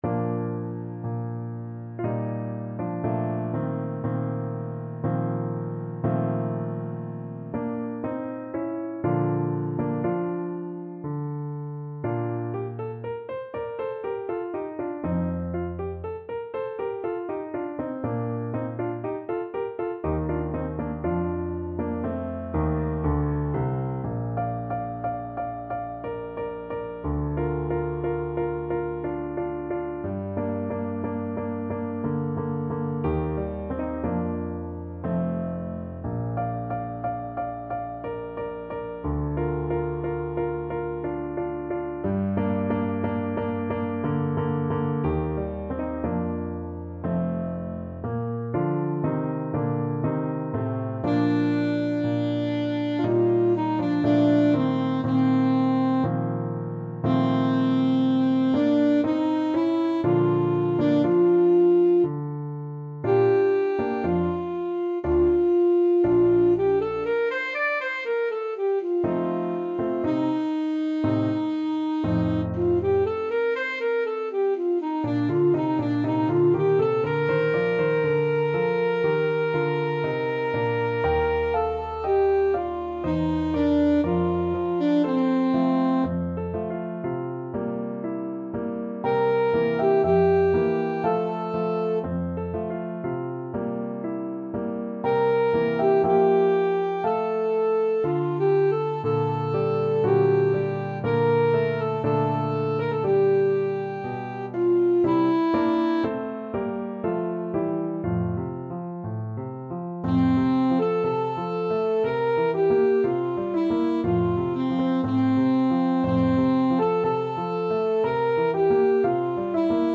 Classical Mozart, Wolfgang Amadeus Zeffiretti lusinghieri from Idomeneo Alto Saxophone version
Alto Saxophone
Bb major (Sounding Pitch) G major (Alto Saxophone in Eb) (View more Bb major Music for Saxophone )
Andante grazioso ~ =60 (View more music marked Andante grazioso)
3/4 (View more 3/4 Music)
Classical (View more Classical Saxophone Music)